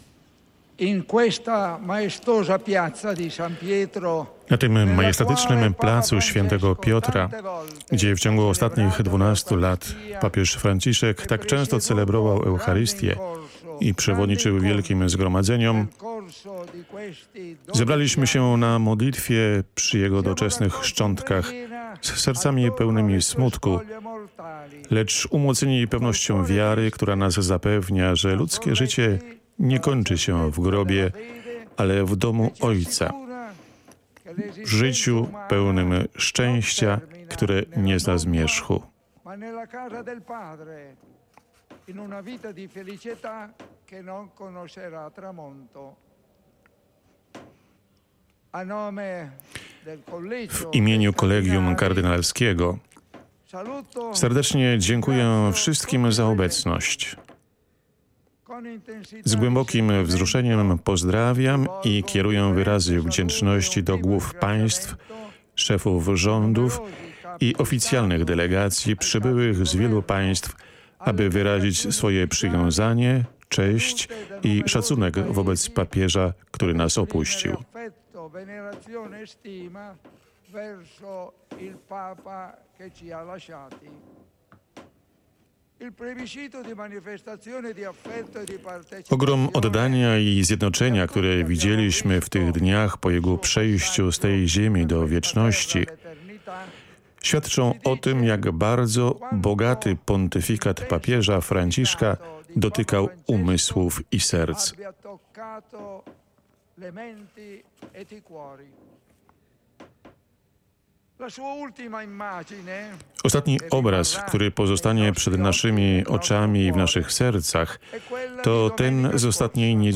Kard. Givanni Battista Re przewodniczył Mszy św. pogrzebowej Papieża Franciszka, która zgromadziła blisko 200 tys. osób, ponad 160 delegacji zagranicznych i kilkadziesiąt delegacji różnych Kościołów i religii.
Homilia, którą wygłosił dziekan Kolegium Kardynalskiego, przypomniała jeszcze raz najważniejsze punkty pontyfikatu i nauczania Franciszka.
Himilia_kard.-Battista-Re.mp3